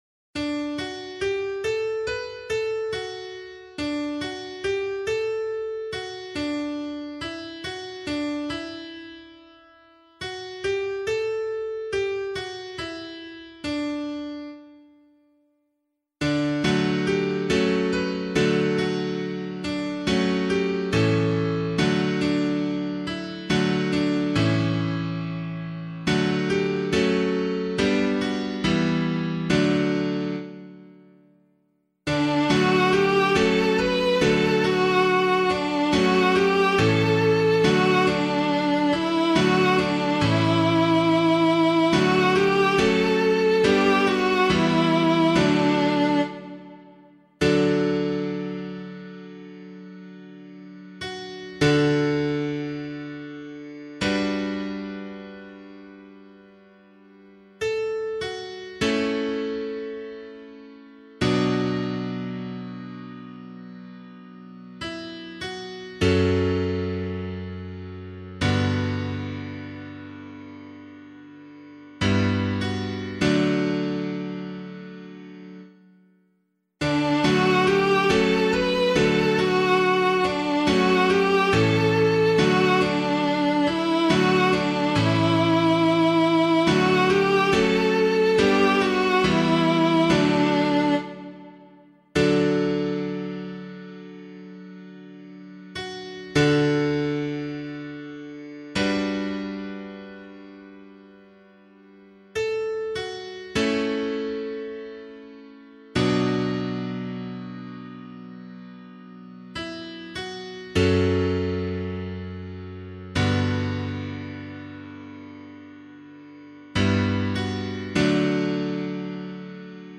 pianovocal
413 Lateran Basilica Psalm [APC - LiturgyShare + Meinrad 5] - piano.mp3